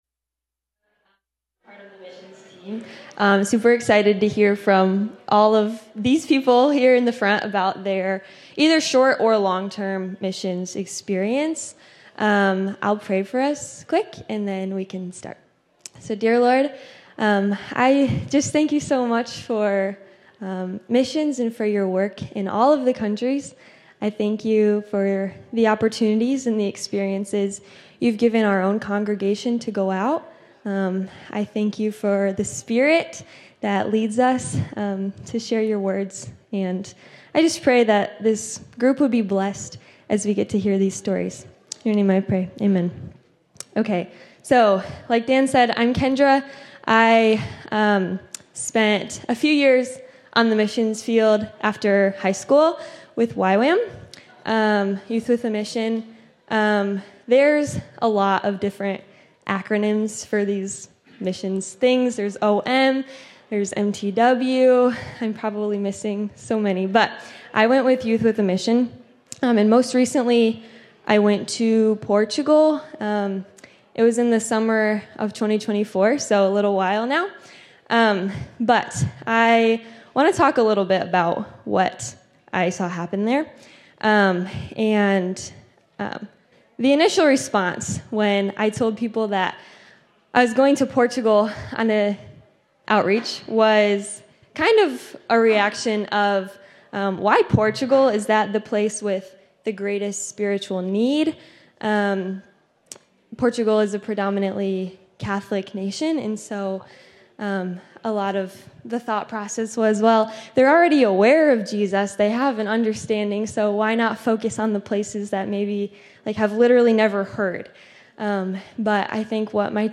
In this SUNDAY SCHOOL CLASS we hear first hand stories from members of our own church as they share about their mission experiences.